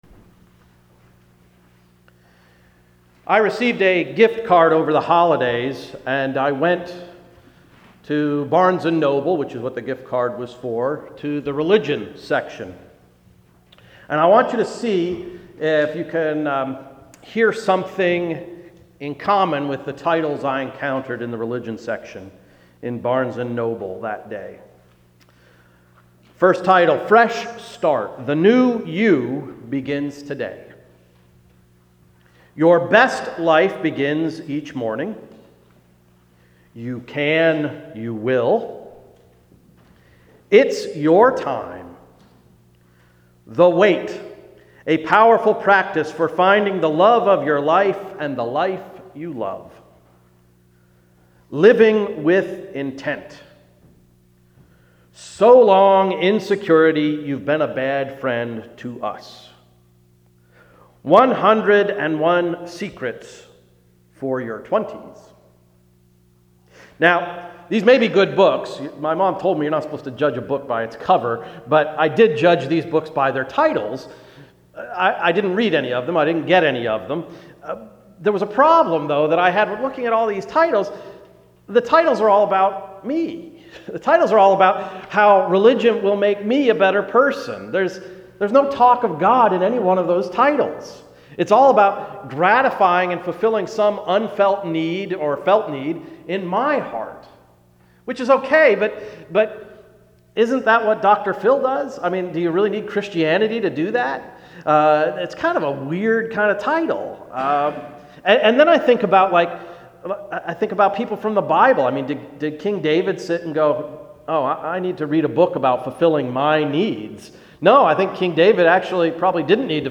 Sermon of February 21, 2016–“Family Feud”